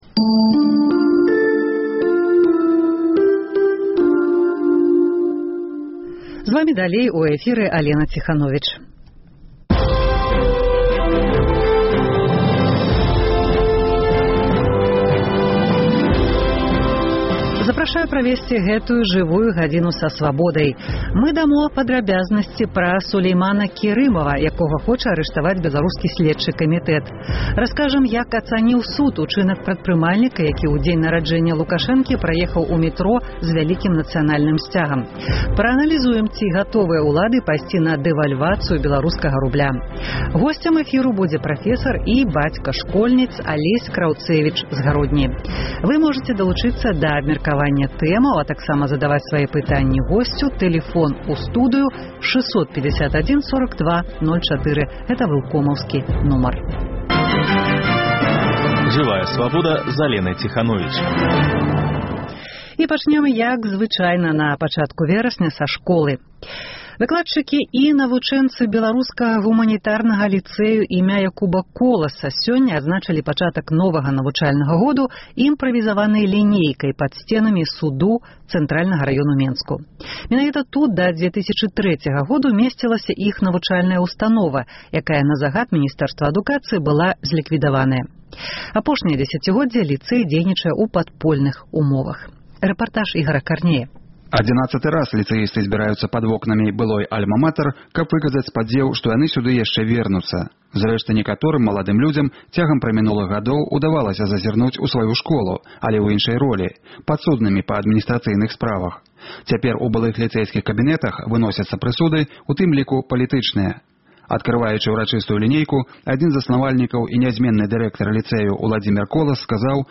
Бліц-аналіз: Ці пойдуць улады на дэвальвацыю рубля? Далучайцеся да абмеркаваньня гэтых тэмаў у эфіры.